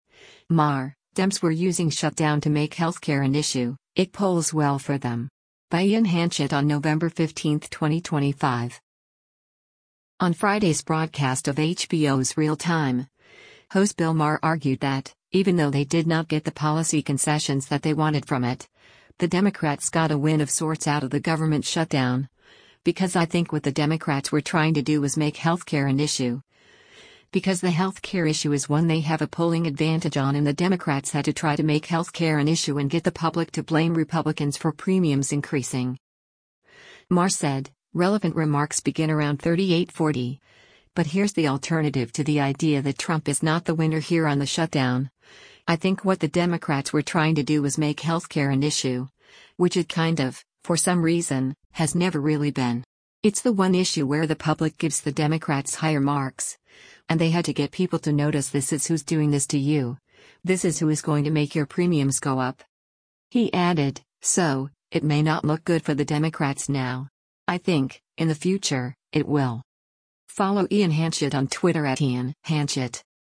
On Friday’s broadcast of HBO’s “Real Time,” host Bill Maher argued that, even though they did not get the policy concessions that they wanted from it, the Democrats got a win of sorts out of the government shutdown, because “I think what the Democrats were trying to do was make health care an issue,” because the health care issue is one they have a polling advantage on and the Democrats had to try to make health care an issue and get the public to blame Republicans for premiums increasing.